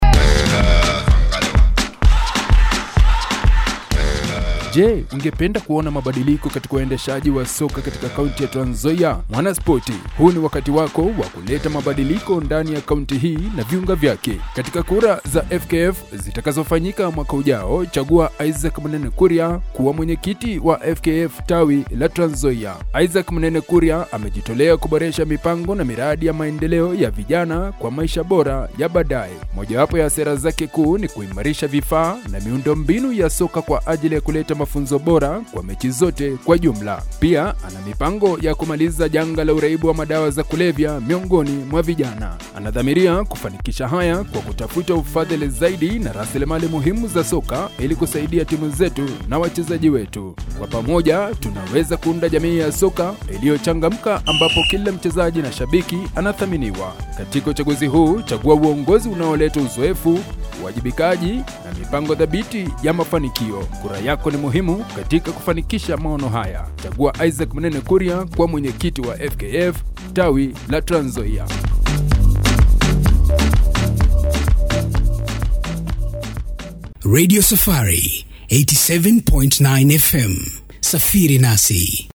Kommerzielle Demo
Unternehmensvideos
BaritonBassTief
AutorisierendUnternehmenErfahrenPräziseKonversation